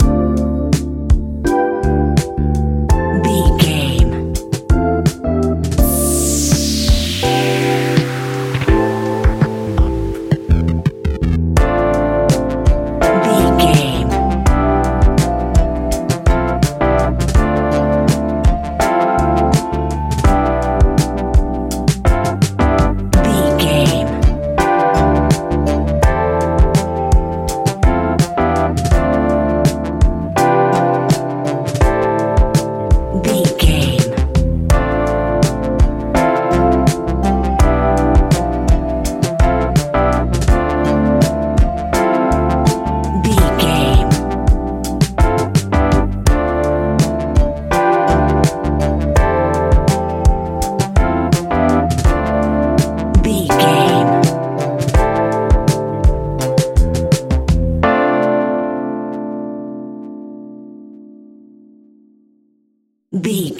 Ionian/Major
chilled
laid back
Lounge
sparse
new age
chilled electronica
ambient
atmospheric